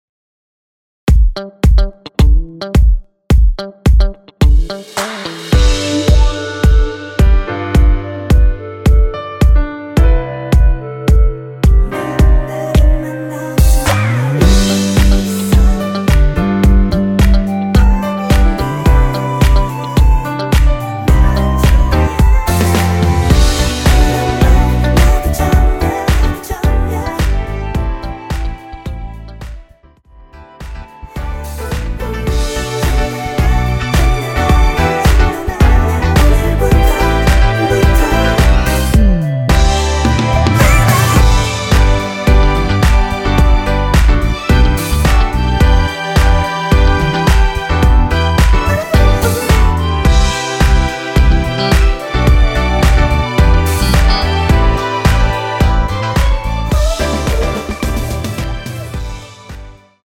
원키에서(+3)올린 멜로디와 코러스 포함된 MR입니다.(미리듣기 확인)
앞부분30초, 뒷부분30초씩 편집해서 올려 드리고 있습니다.
중간에 음이 끈어지고 다시 나오는 이유는